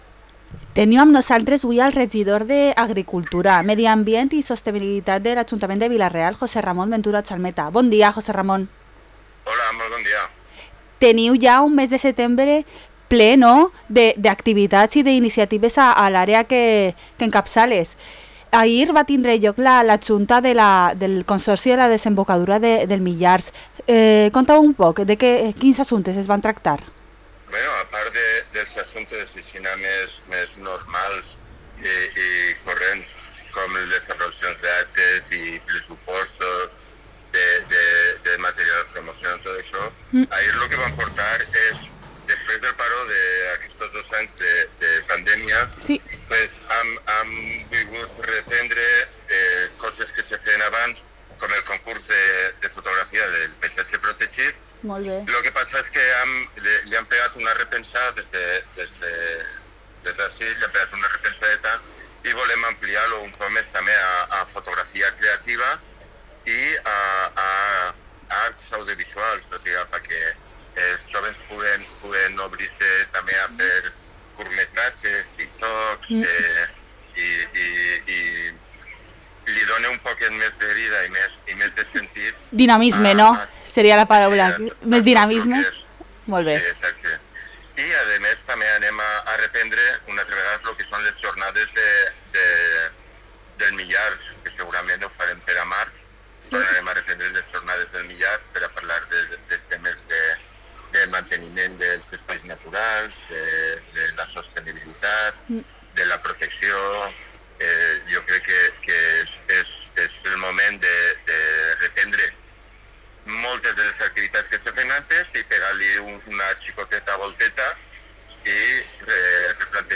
El regidor d’Agricultura, Medi Ambient i Sostenibilitat de l’Ajuntament de Vila-real, José Ramón Ventura Chalmeta, ens parla dels últims projectes a la Desembocadura del Millars, la Setmana de Mobilitat Sostenible i el Bicivila’t, que ara sí que sí sembla que està a punt.